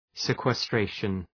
Shkrimi fonetik{,si:kwes’treıʃən}
sequestration.mp3